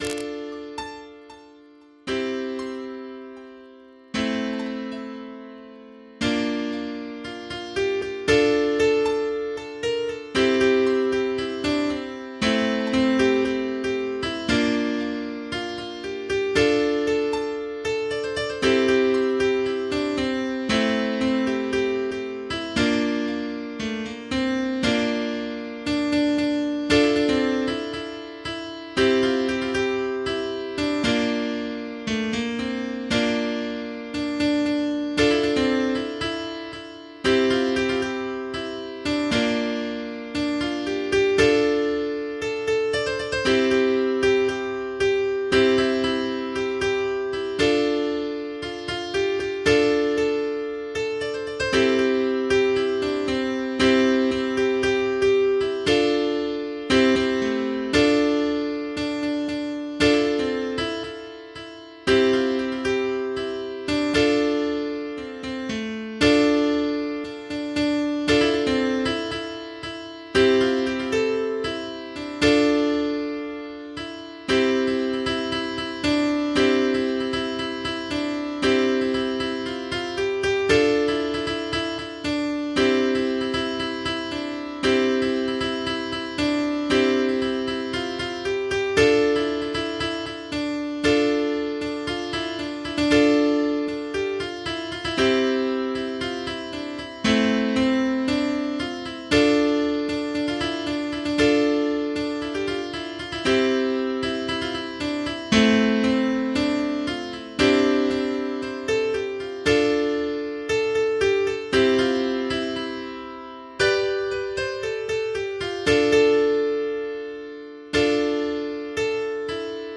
MIDI 8.24 KB MP3 (Converted) 3.44 MB MIDI-XML Sheet Music